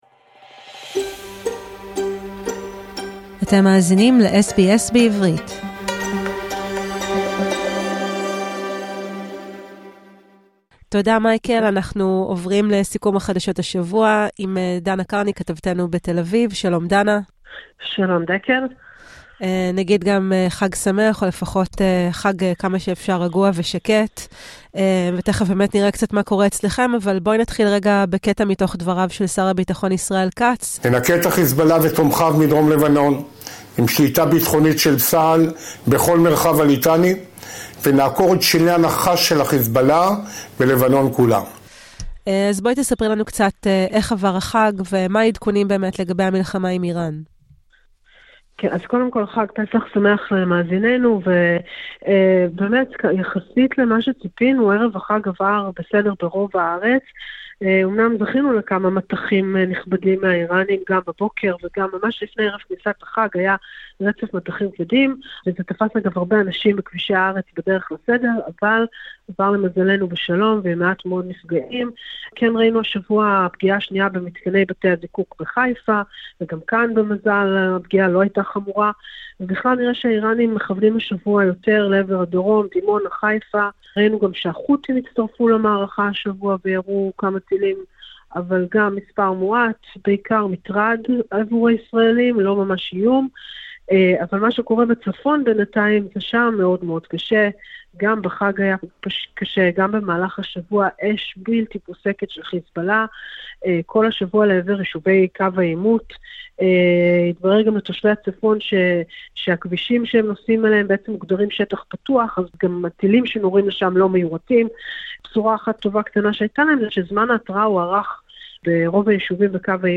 השבוע שהיה - סיכום חדשות מישראל